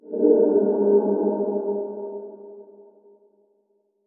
8 bits Elements
Magic Demo
Magic_6_2.wav